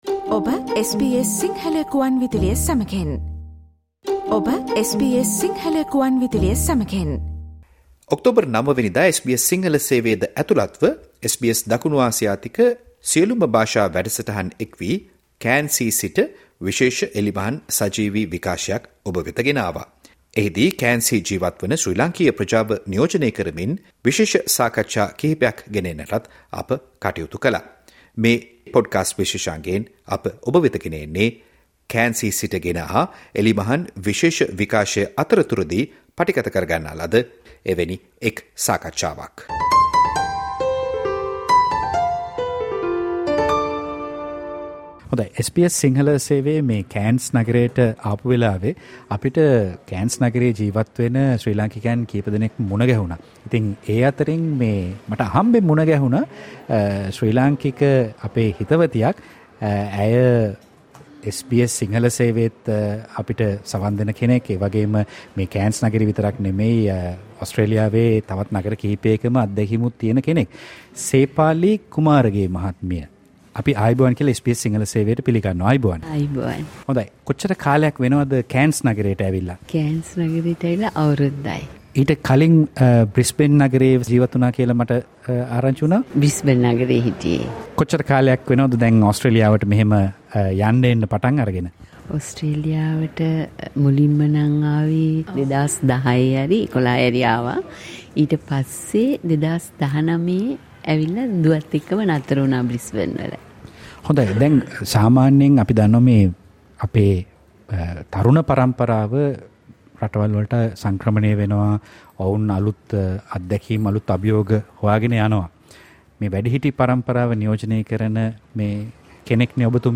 සජීව එළිමහන් විශේෂ විකාශය
සජීව පටිගත කල පොඩ්කාස්ට් විශේෂාංගය
the special Diwali outdoor broadcast at Cairns Central Shopping Centre